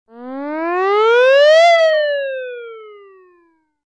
descargar sonido mp3 sirena 12